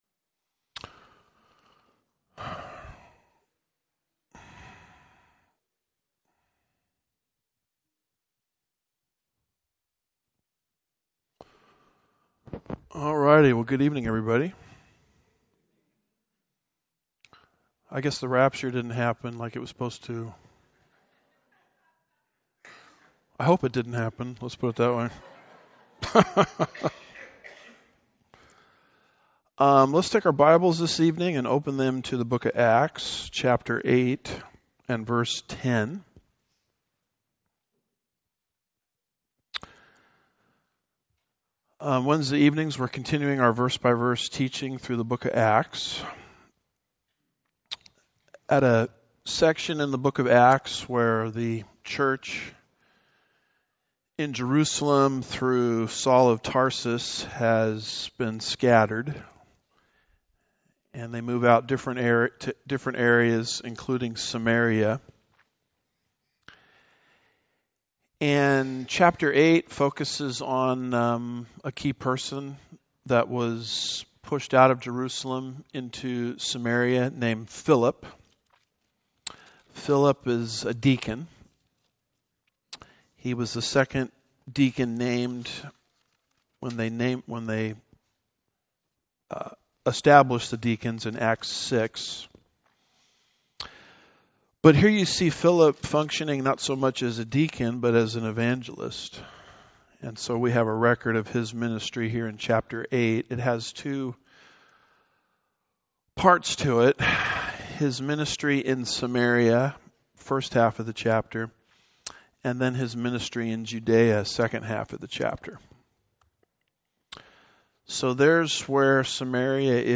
(Part 1) Home / Sermons / Acts 044 - Was Simon Saved?